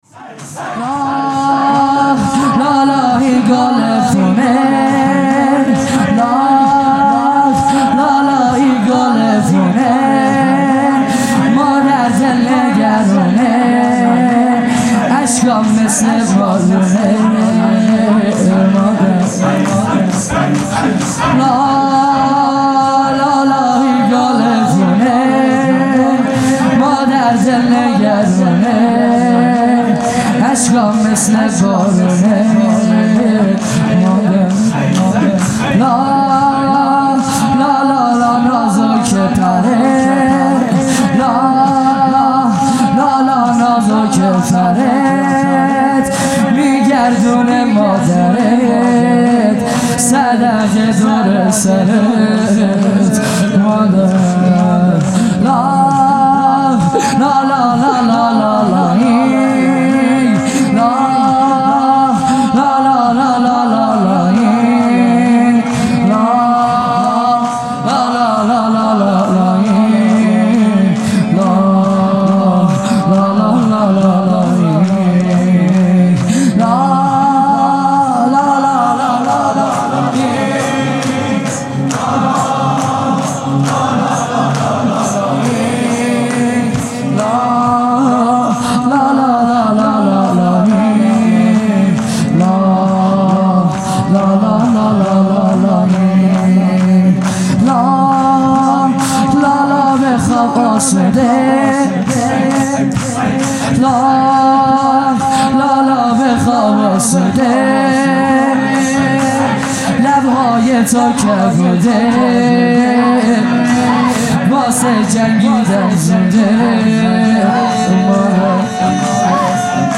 خیمه گاه - هیئت بچه های فاطمه (س) - شور | لالایی گل پونه